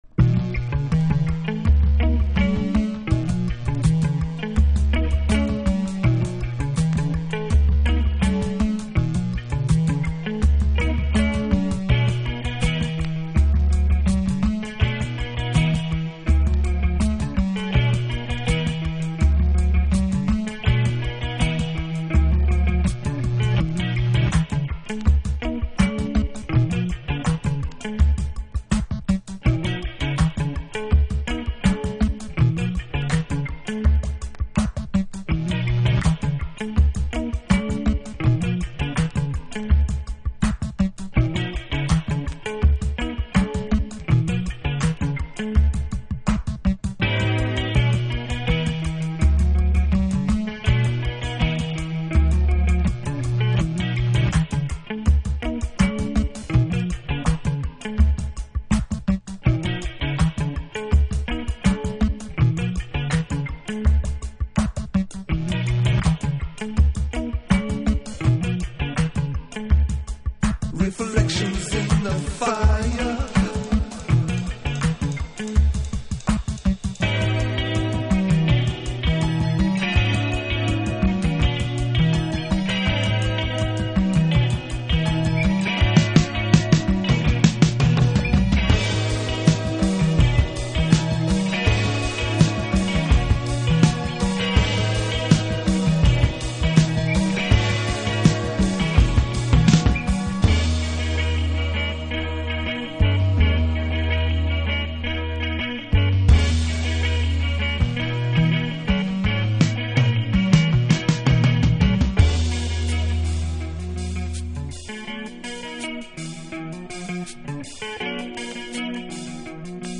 Rock / Prog / Avant
オヤジ遊びが効いたサイケデリックロック。